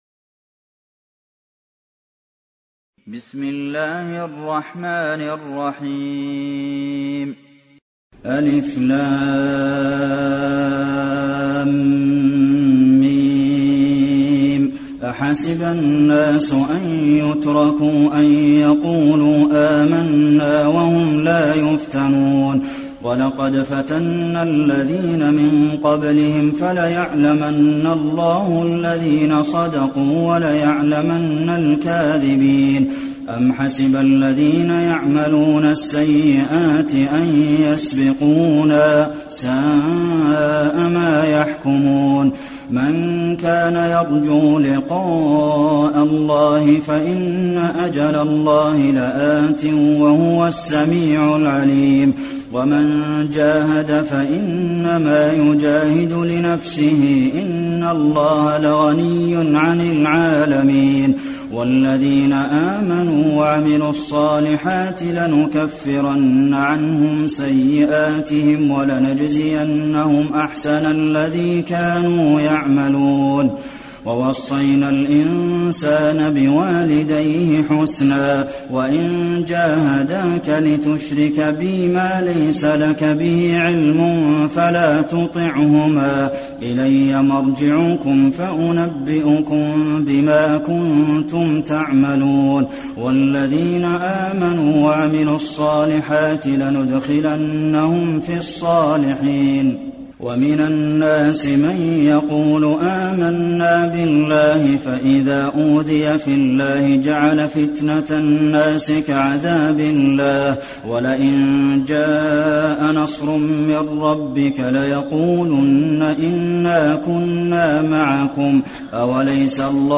المكان: المسجد النبوي العنكبوت